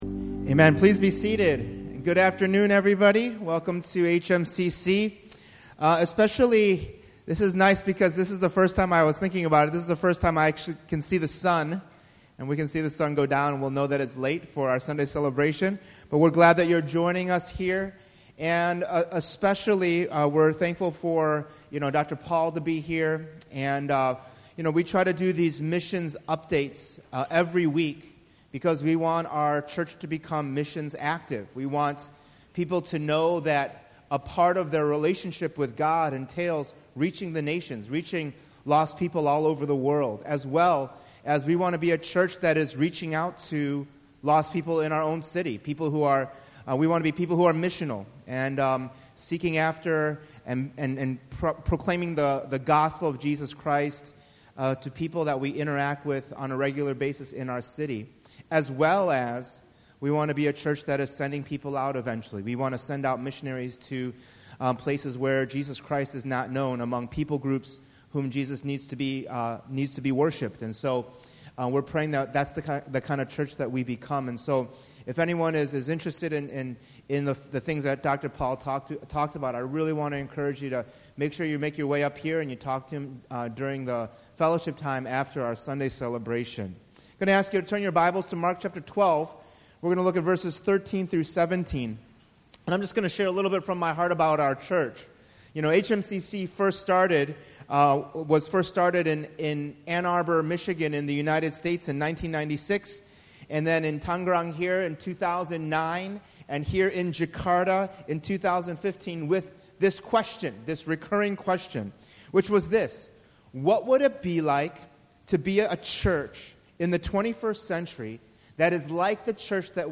Feel free to listen along to the sermon audio with the sermon handout and slides.